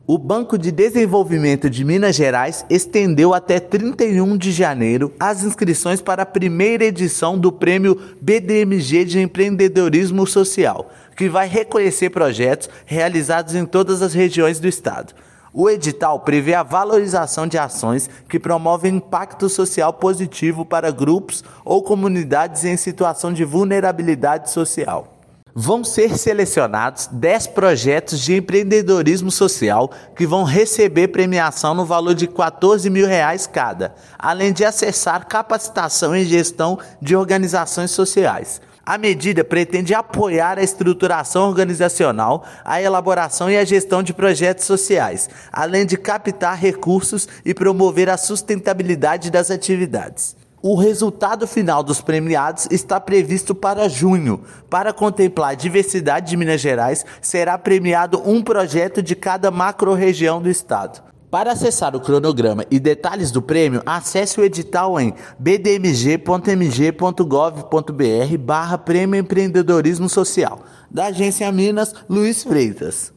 Dez projetos de empreendedorismo social que transformam a realidade de comunidades em situação de vulnerabilidade social serão premiados. Ouça matéria de rádio.
RADIO_MATERIA_BDMG_EMPREENDIMENTO.mp3